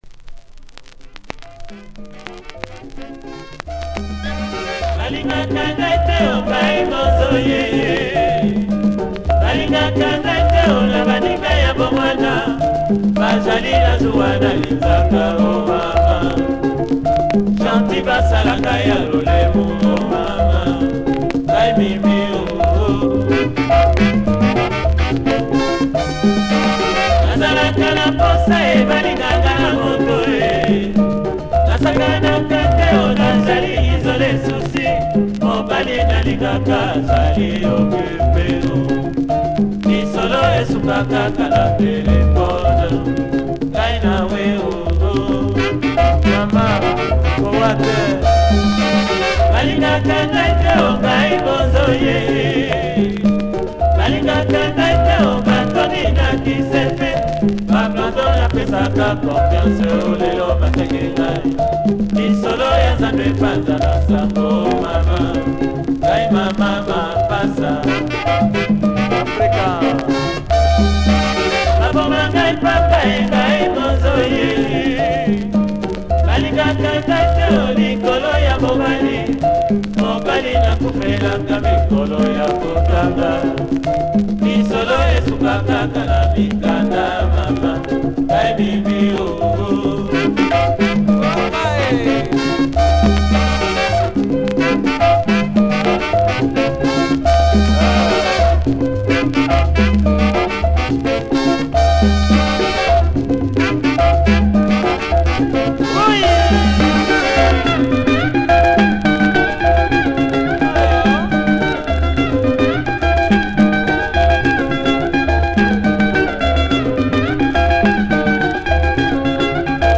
Superb rumba